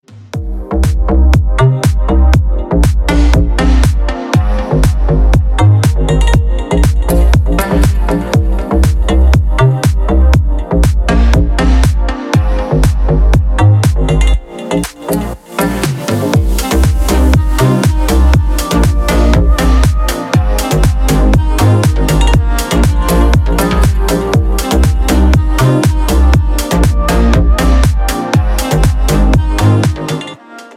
• Песня: Рингтон, нарезка
• Категория: Рингтоны